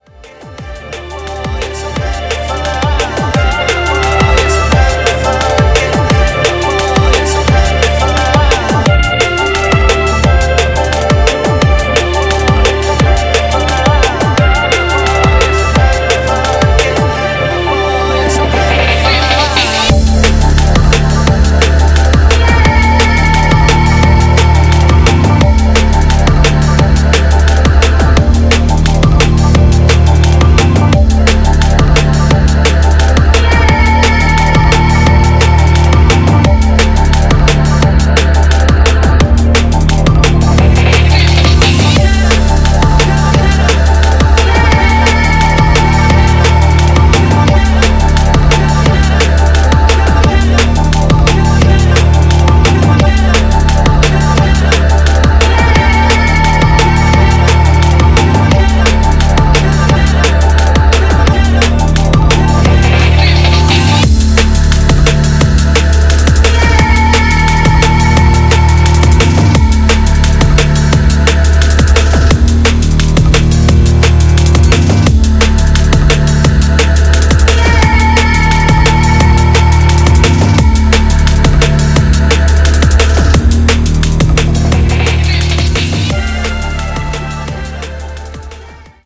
Styl: House, Breaks/Breakbeat Vyd�no